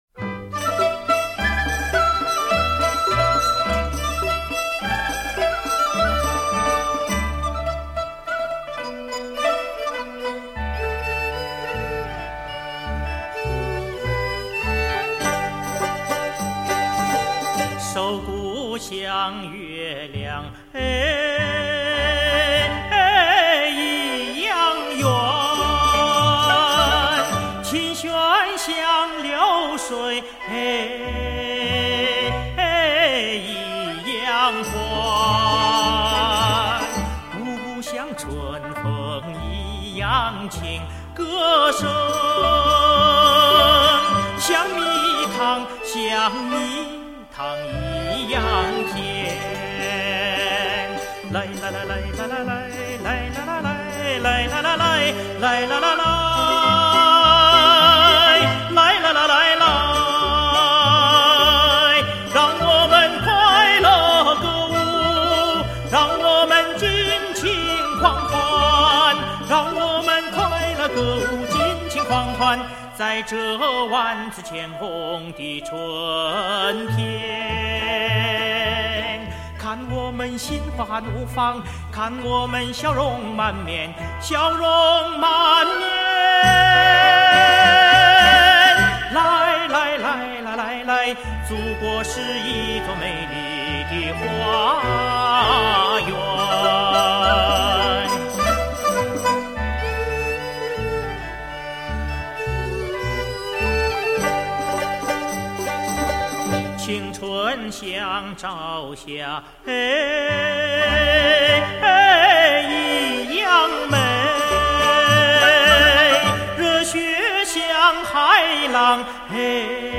特有的“装饰”，“润腔”以及真假声结合等手法表现特有的地域色彩！